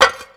21. 21. Percussive FX 20 ZG